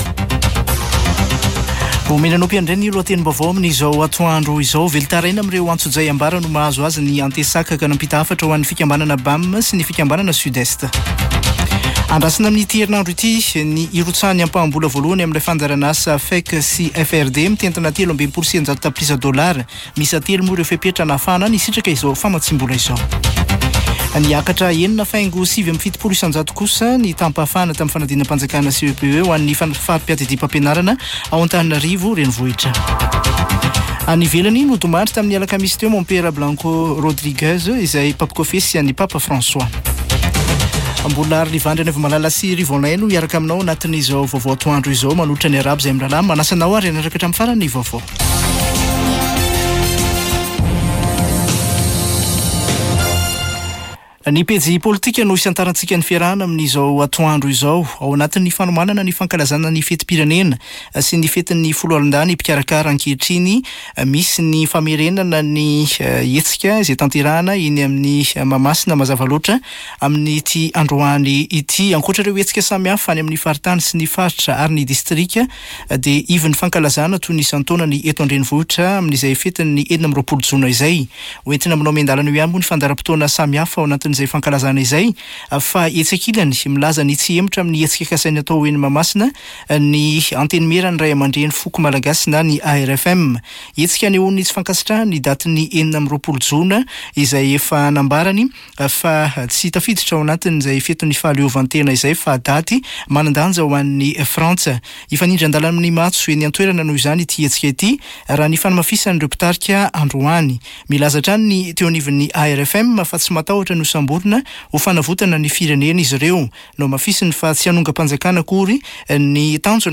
[Vaovao antoandro] Alatsinainy 24 jona 2024